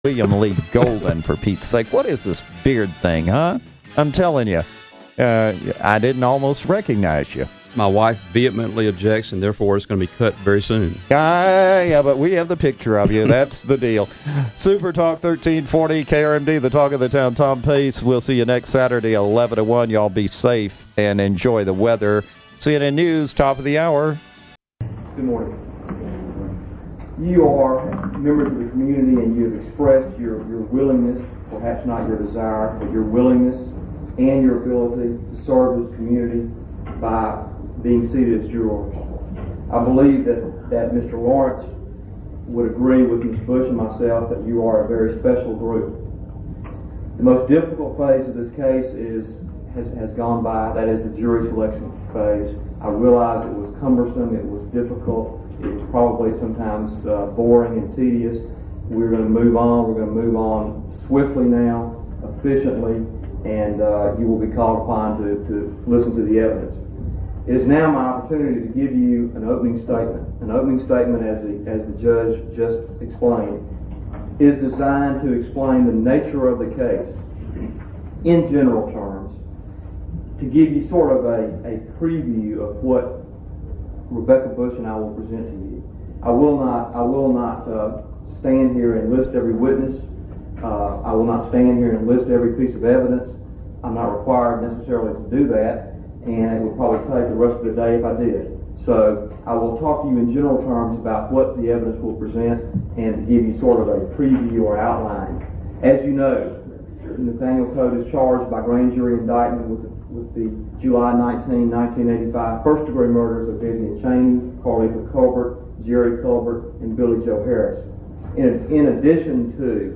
Opening Statement